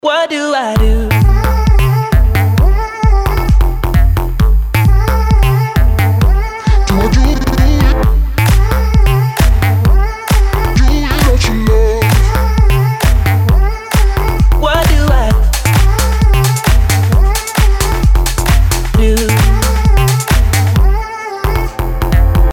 dance
Electronic
house
Заводной и мелодичный мотивчик!